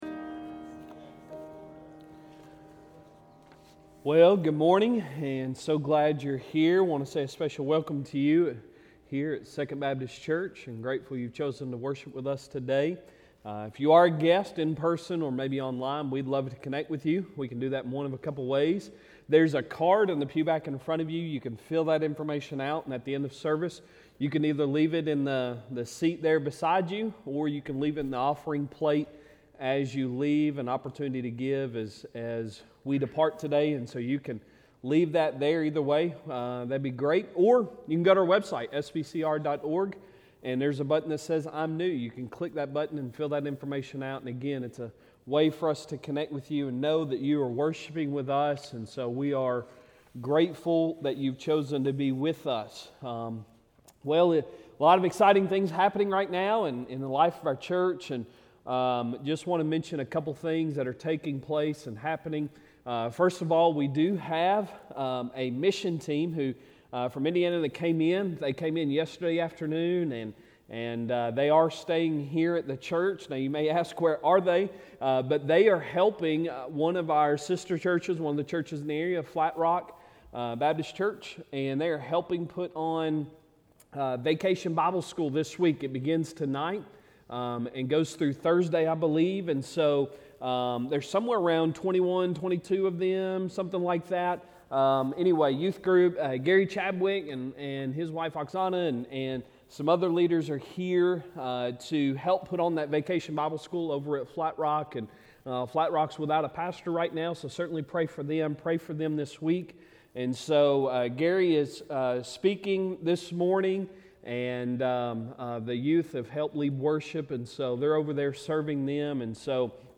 Sunday Sermon June 25, 2023